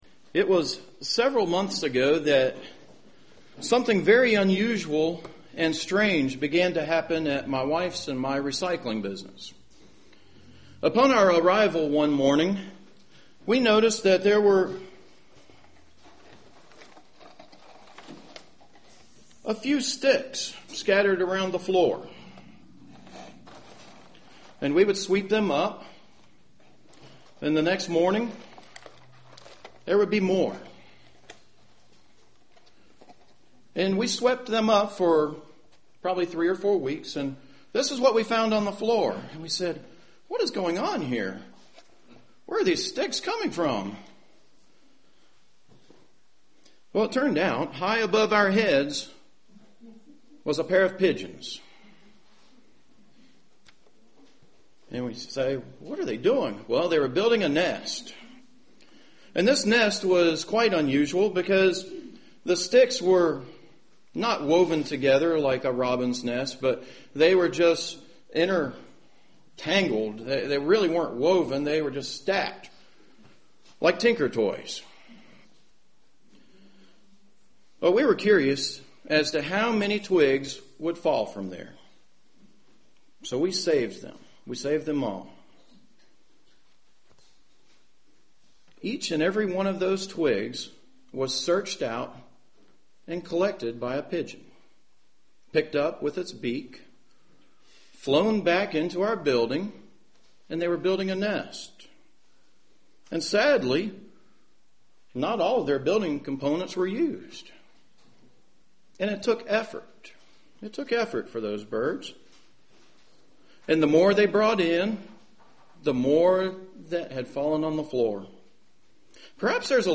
UCG Sermon Studying the bible?
Given in Charlotte, NC